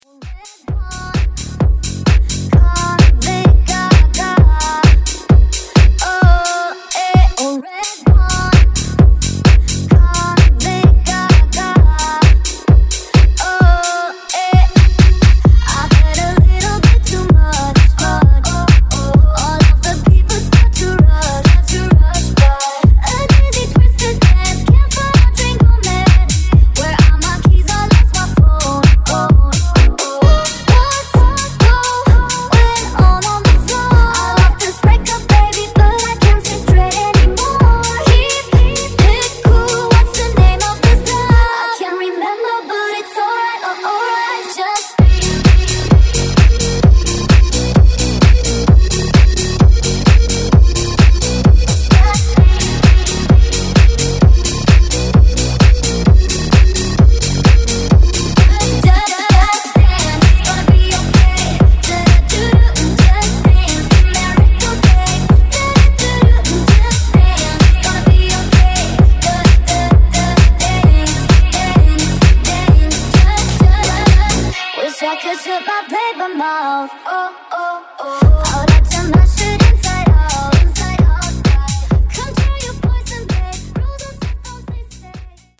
Genres: DANCE , RE-DRUM , TOP40
Clean BPM: 120 Time